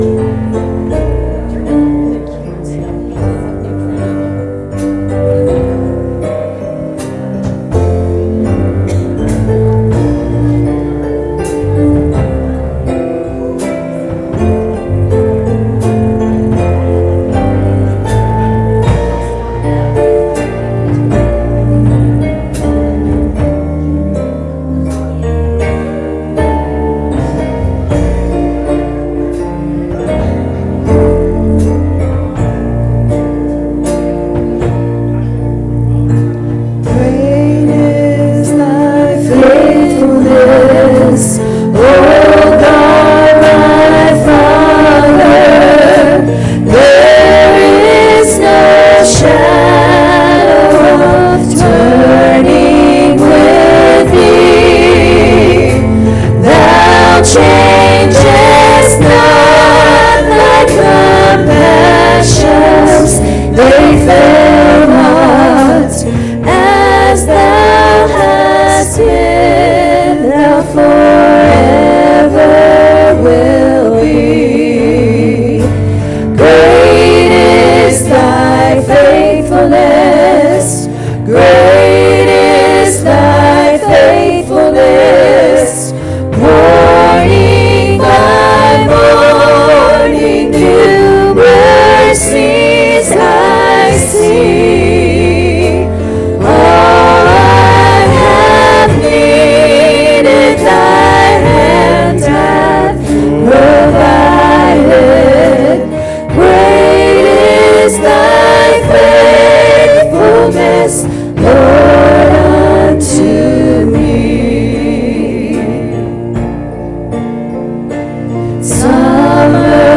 Service Type: Sunday Morning Services